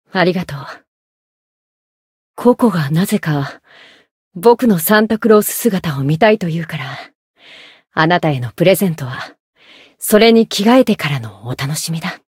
灵魂潮汐-迦瓦娜-圣诞节（送礼语音）.ogg